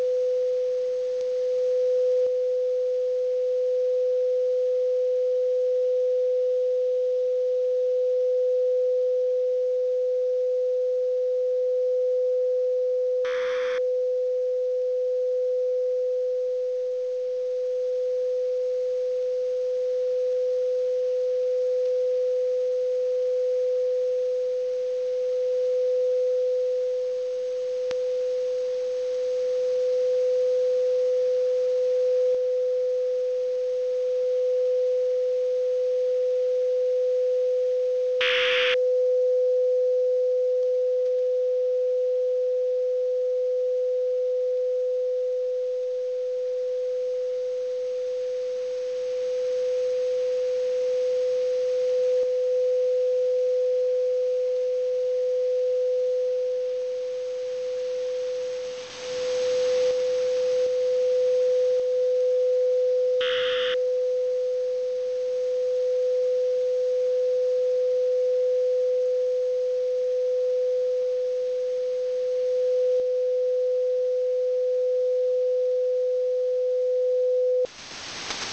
Неизвестный сигнал